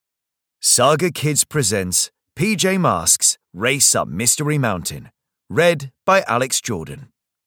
Audio knihaPJ Masks - Race Up Mystery Mountain (EN)
Ukázka z knihy